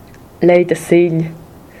Roh-putèr-Lej_da_Segl.ogg.mp3